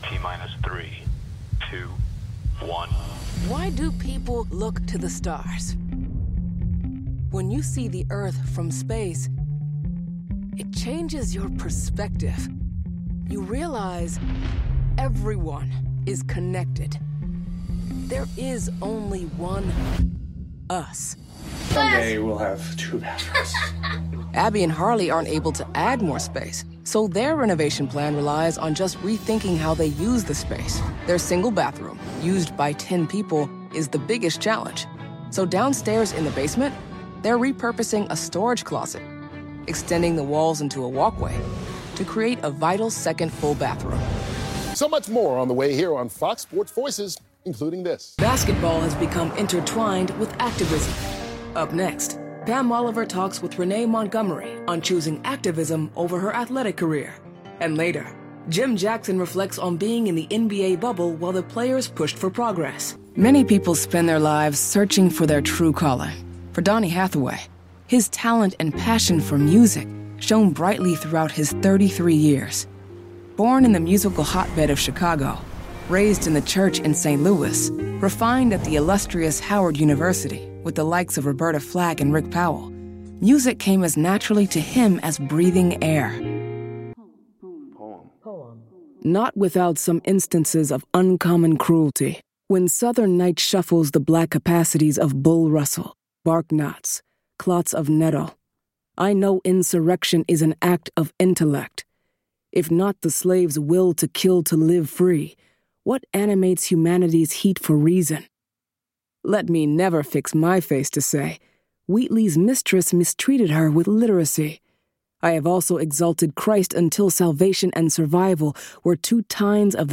Demo
cool
smooth/sophisticated
warm/friendly
NARRATION FILTERS
documentary